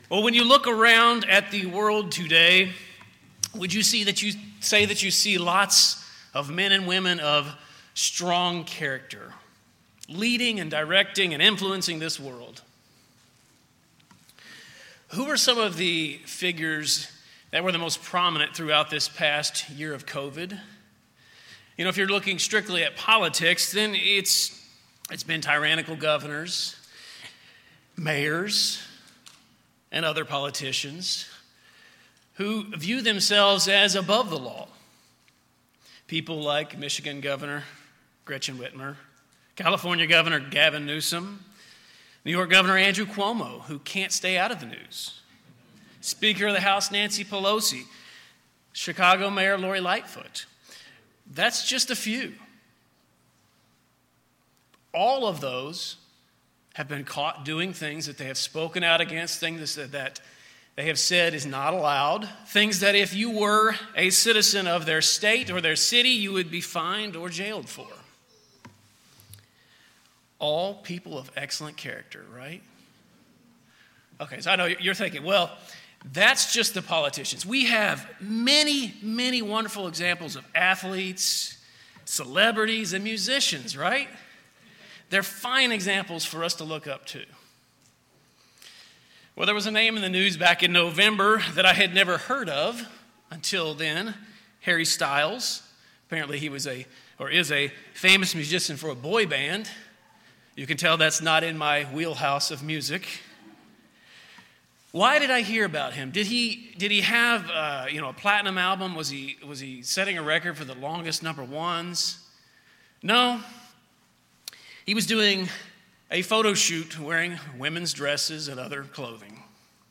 We have to fill the void left with the character of the new man. In this sermon we will focus on three characteristics of the new man listed in Colossians 3.